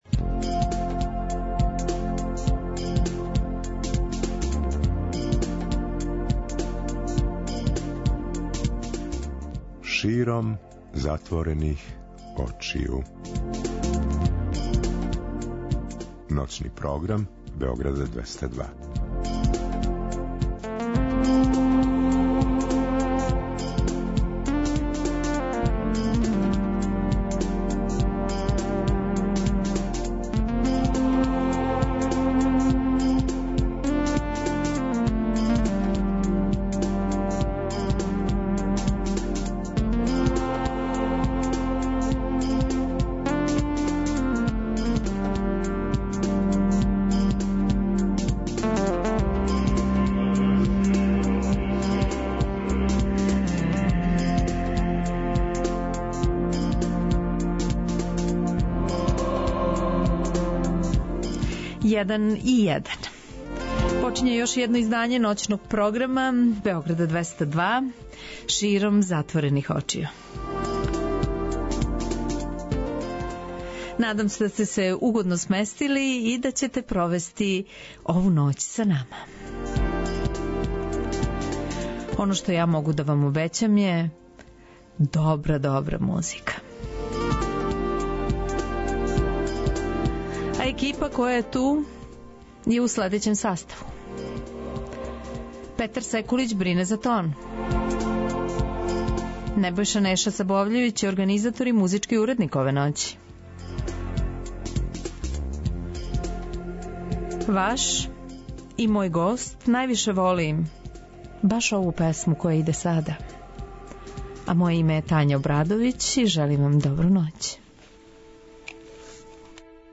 Слушаоци ће имати прилике да премијерно преслушају његове нове композиције. Ексклузивно ће говорити и о новим пројектима које спрема.